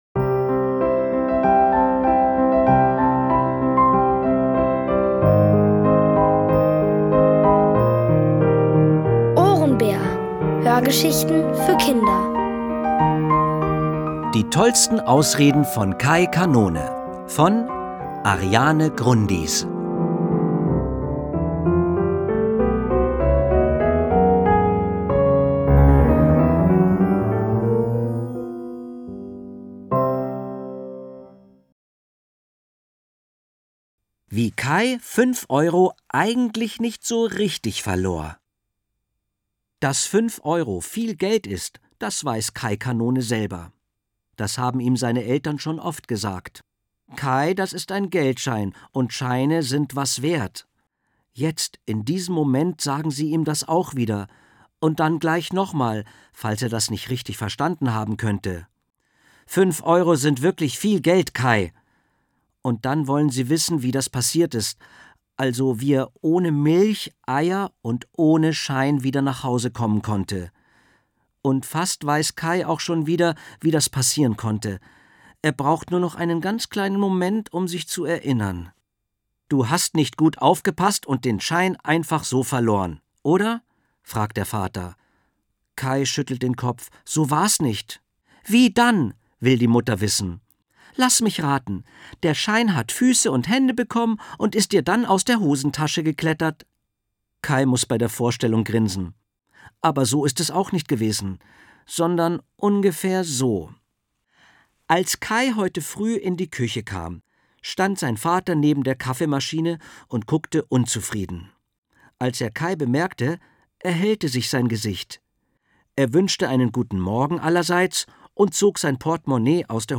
Von Autoren extra für die Reihe geschrieben und von bekannten Schauspielern gelesen.
Es liest: Dieter Landuris.